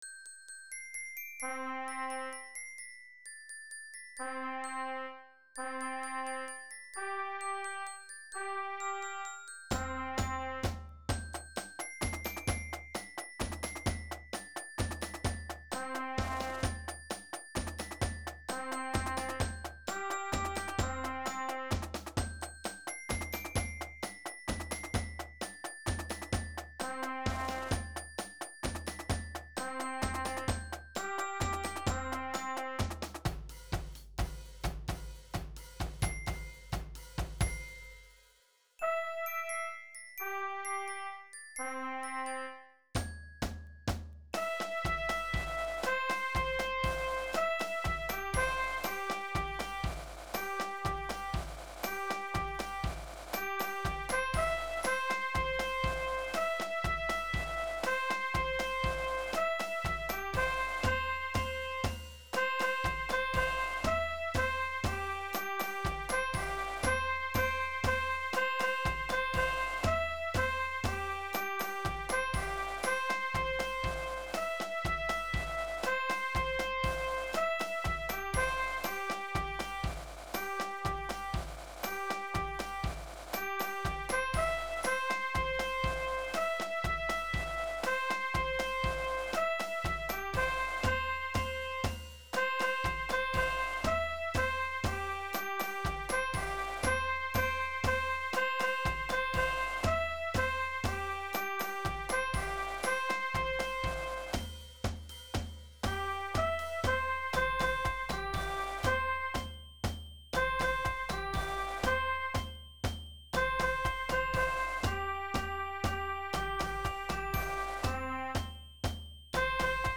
Música para banda
Resumen : RESUMEN : Esta cartilla esta hecha para los formatos de banda marcial tradicional (lira, clarín, caja, granadera, tambora, bombo).
AnexoA_Popurri andino.wav